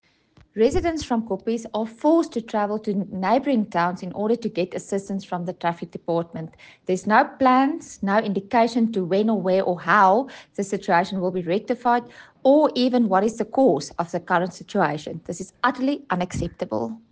Afrikaans soundbites by Cllr Carina Serfontein and Sesotho soundbite by Jafta Mokoena MPL